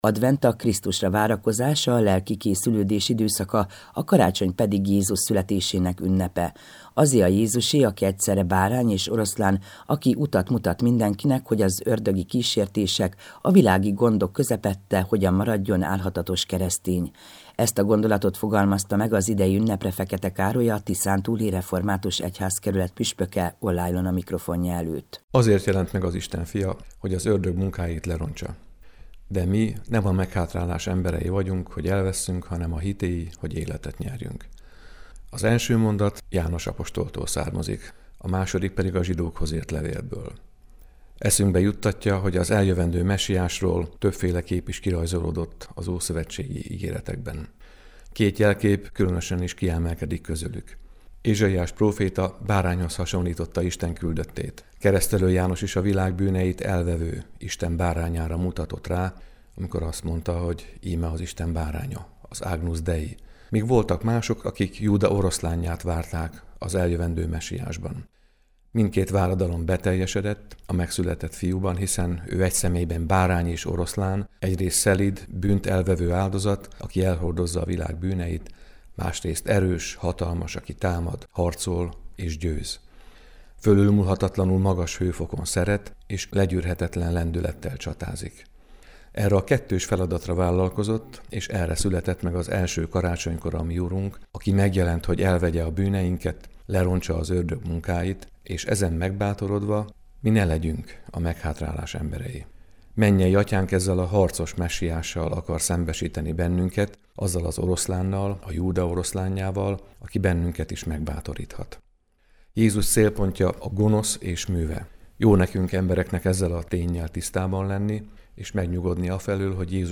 Ezt a gondolatot fogalmazta meg az idei ünnepre Fekete Károly, a Tiszántúli Református Egyházkerület püspöke
az Európa Rádióban